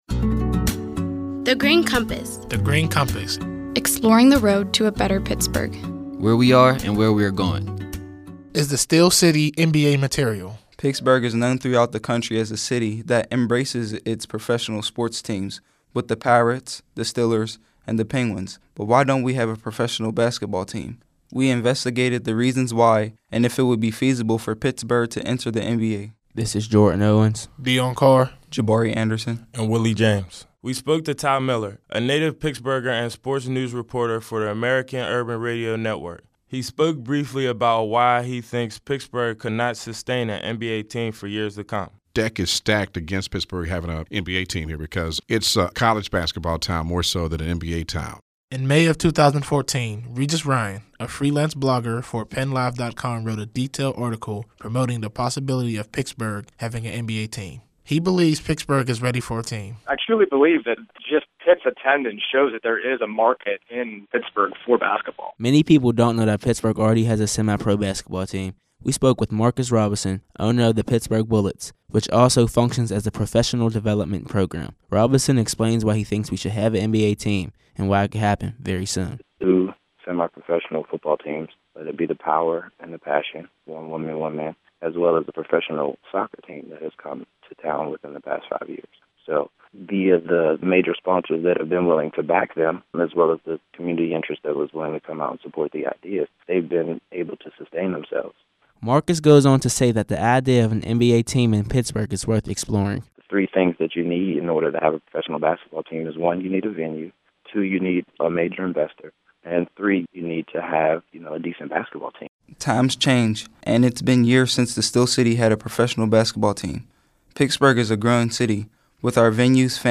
In July 2014, thirty-two recent high-school graduates created these radio features while serving as Summer Interns at The Heinz Endowments.